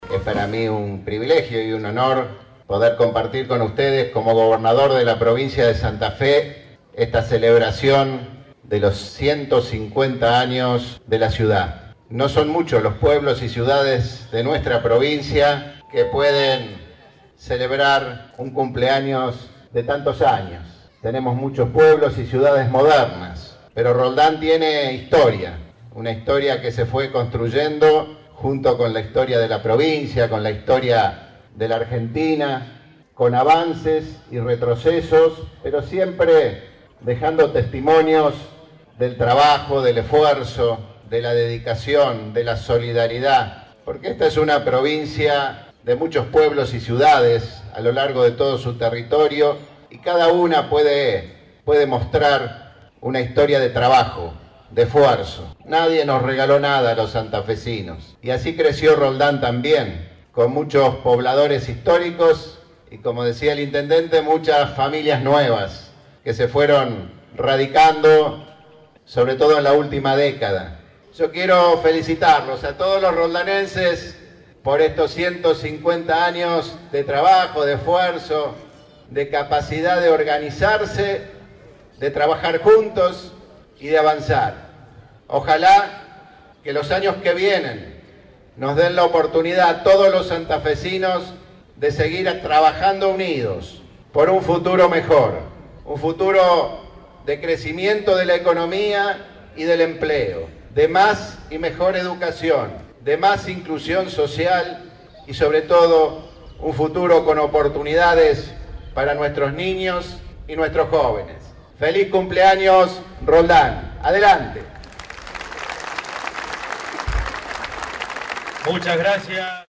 El gobernador de Santa Fe, Miguel Lifschitz, participó este domingo del acto central por el 150° aniversario de la fundación de Roldán.
• Declaraciones a la prensa del gobernador Miguel Lifschitz